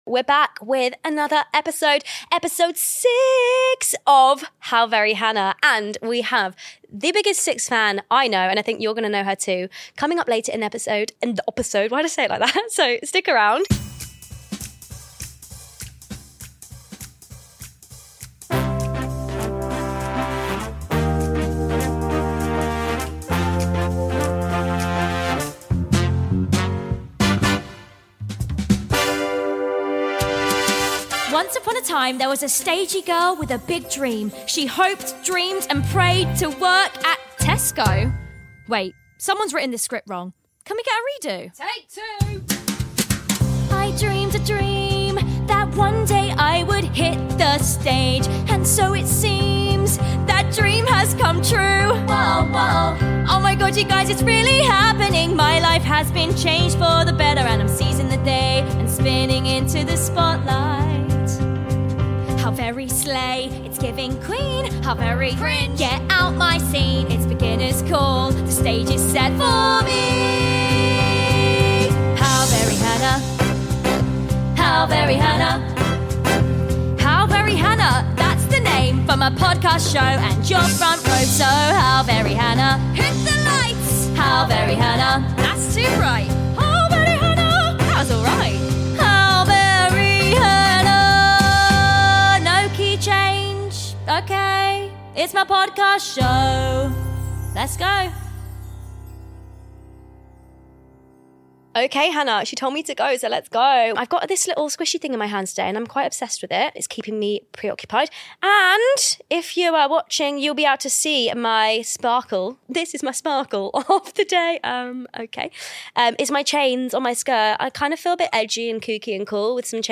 WE HAVE A MEGA FAN IN THE STUDIO! And it’s giving SIX queens, Drag Race dreams, and plenty of theatrics 👑 🎭 🎤